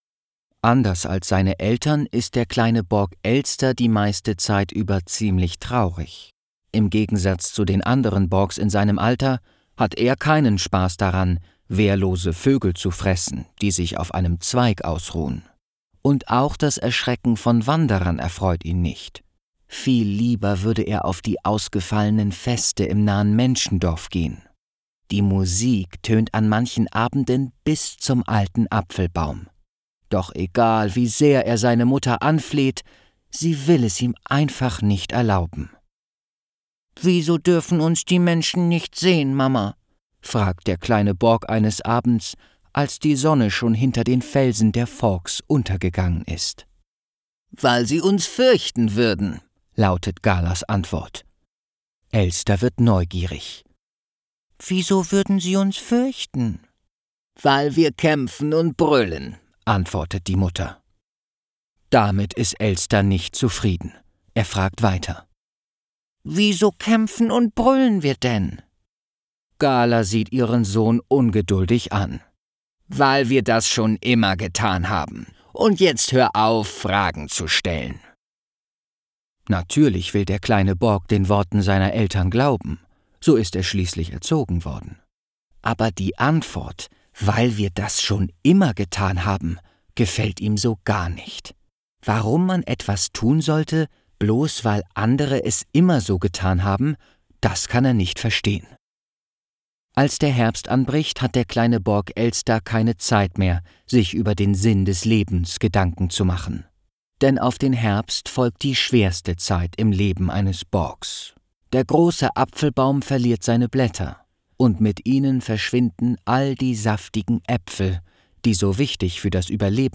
Audio/Hörbuch